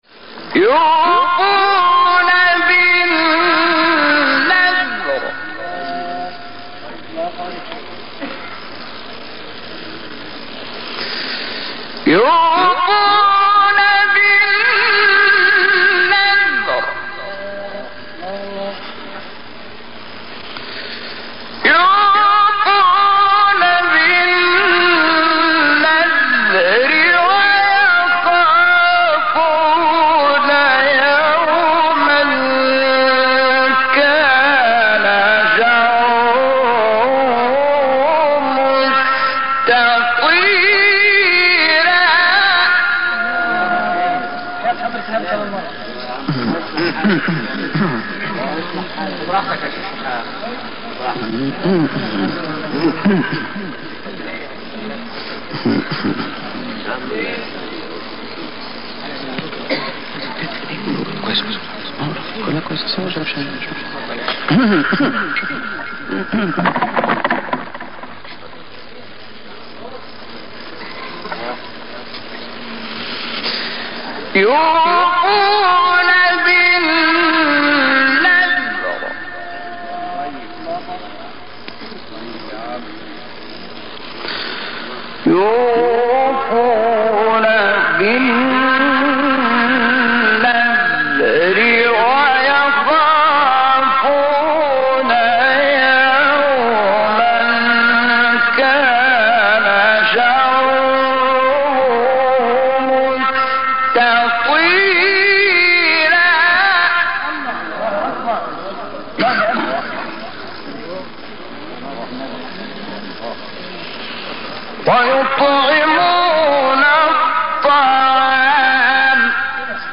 تلاوت آیه 7-9 سوره انسان